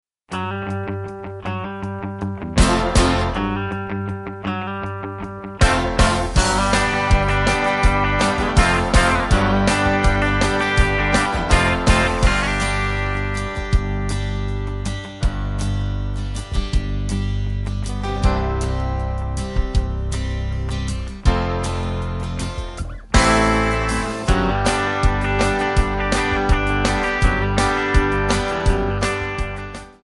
MPEG 1 Layer 3 (Stereo)
Backing track Karaoke
Country, 1990s